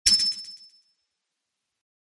CollectGemAlternate.ogg